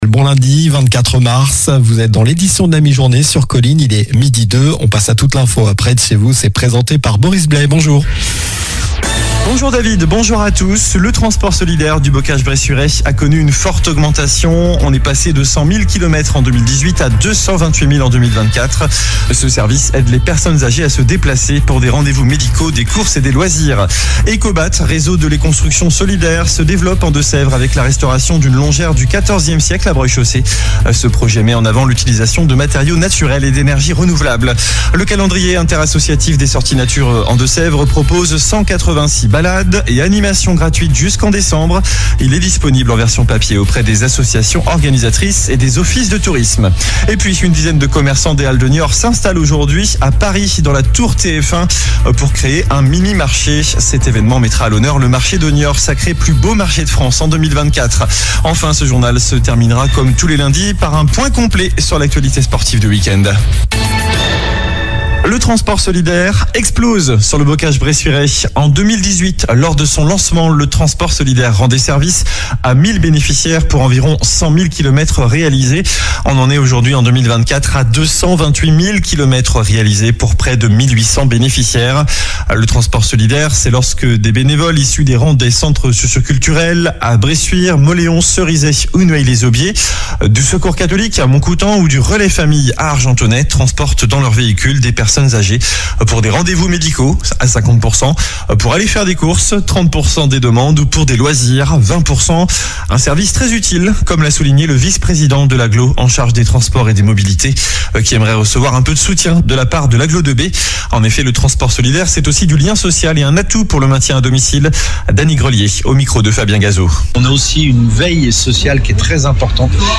Journal du lundi 24 mars (midi)